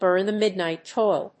アクセントbúrn the mídnight óil